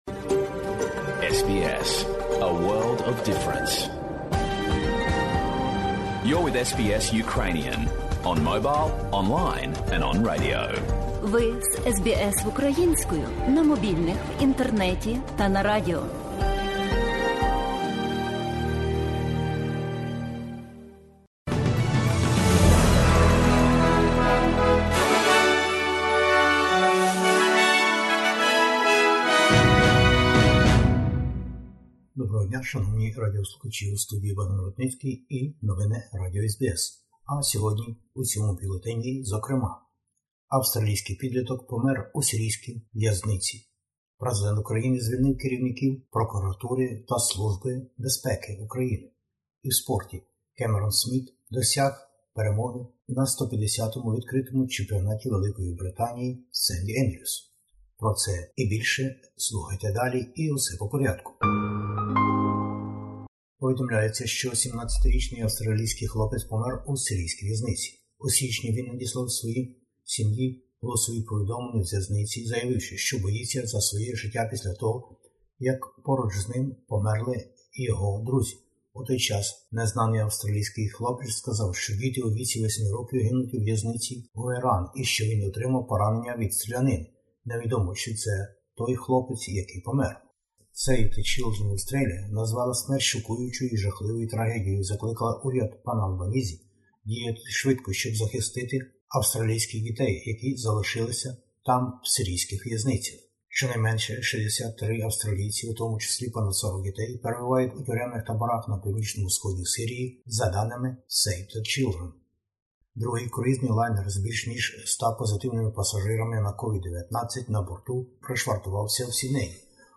Бюлетень SBS новин українською мовою. Війна в Україні та звільнення там високопосадовців.